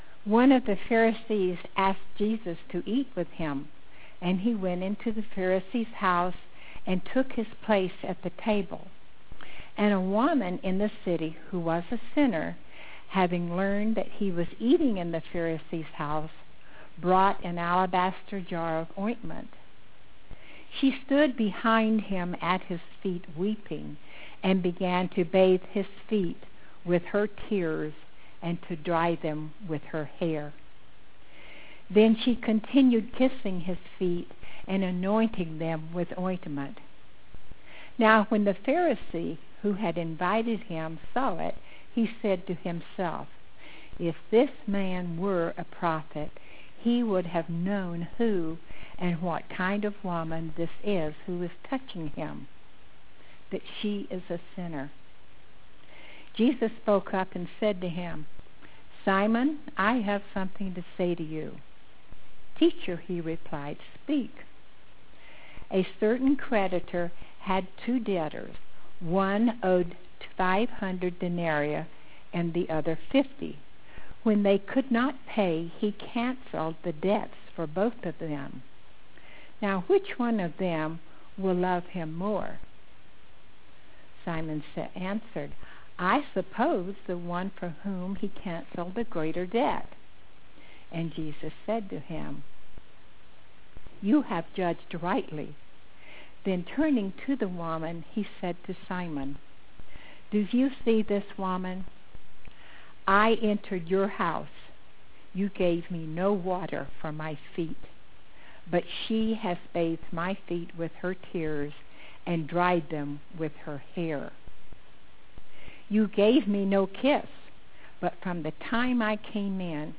scripture and sermon